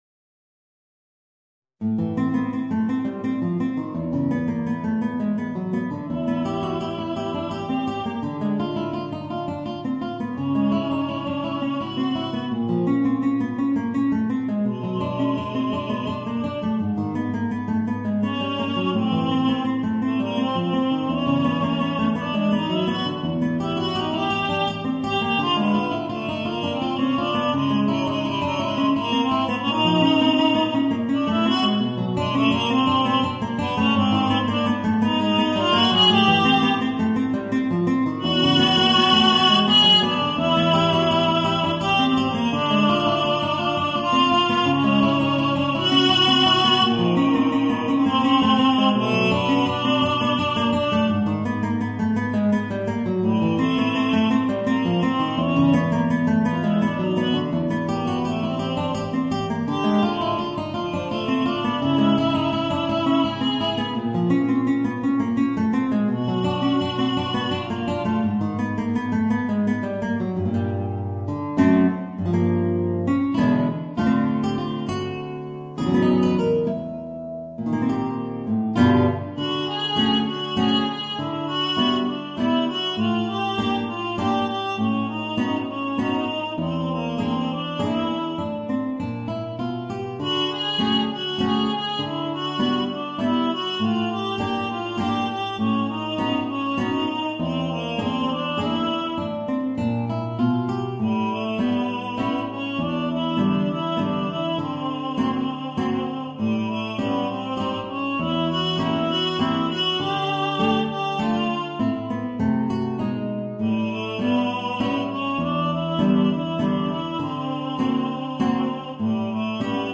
Voicing: Guitar and Soprano